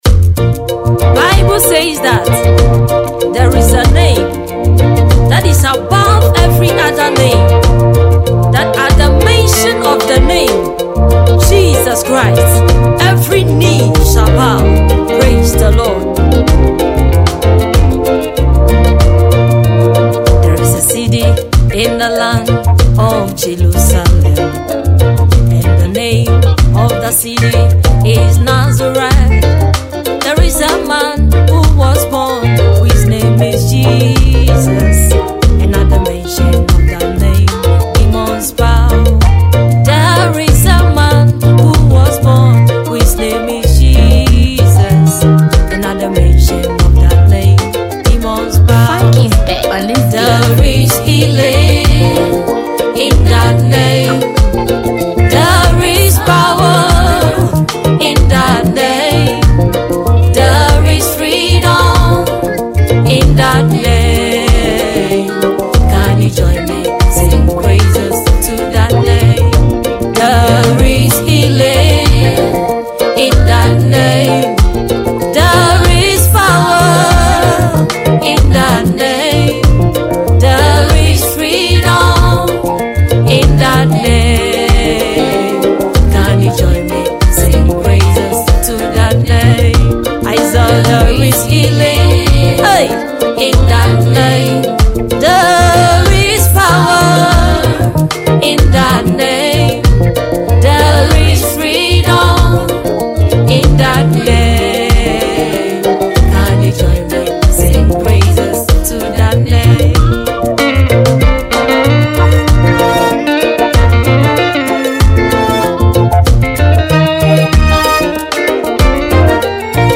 Gospel
is a stirring anthem